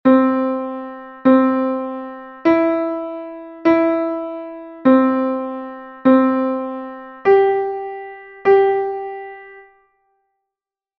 G,C and E note recognition exercise 1
note_recognition_1.mp3